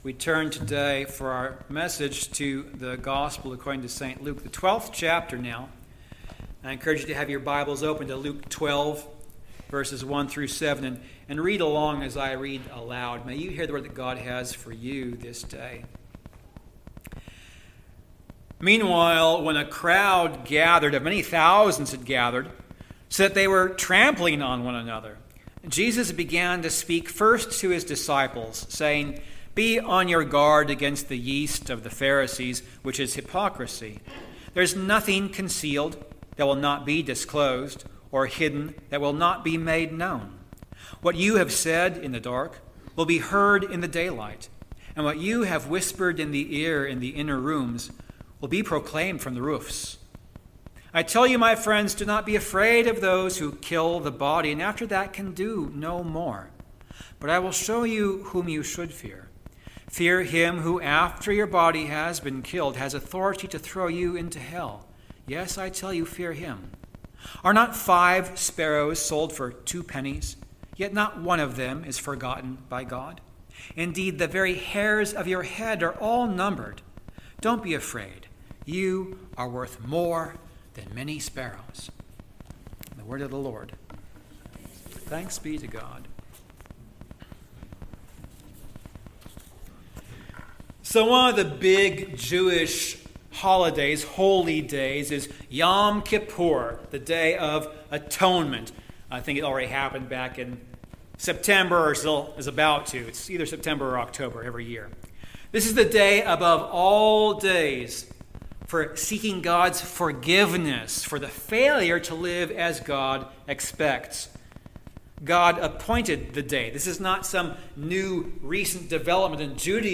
Service Type: Communion Service